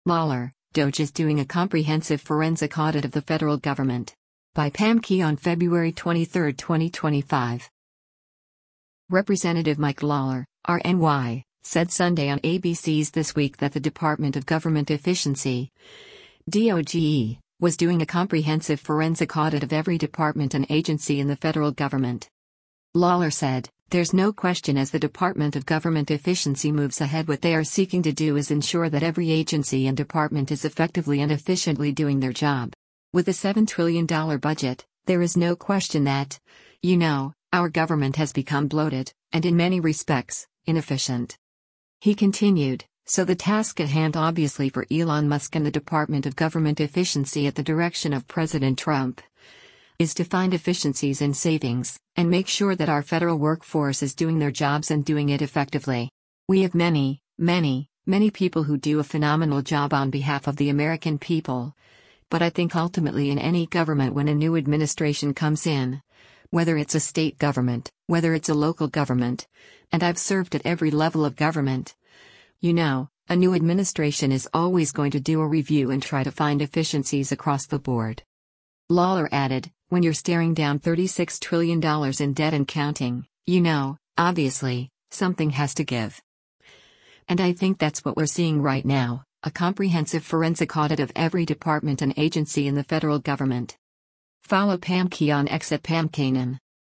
Representative Mike Lawler (R-NY) said Sunday on ABC’s “This Week” that the Department of Government Efficiency (DOGE) was doing a “comprehensive forensic audit of every department and agency in the federal government.”